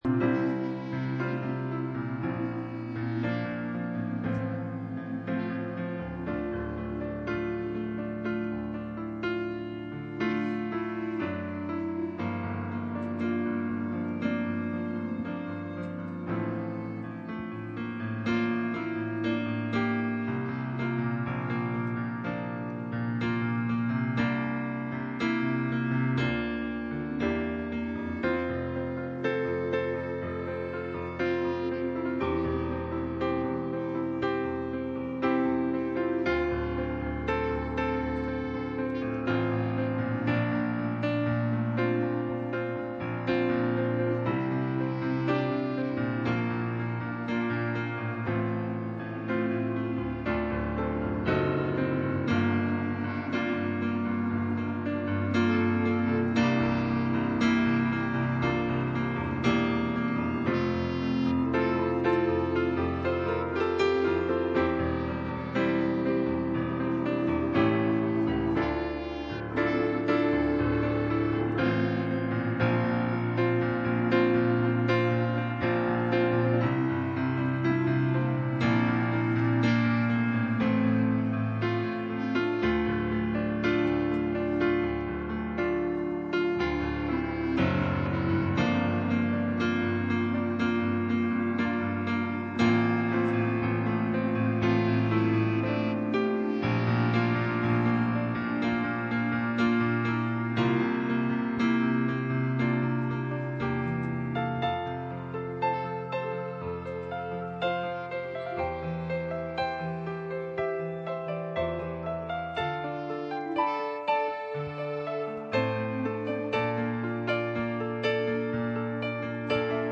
Pastor Passage: Colossians 1:26-29 Service Type: Sunday Morning %todo_render% « Revelation Study on II Samuel 6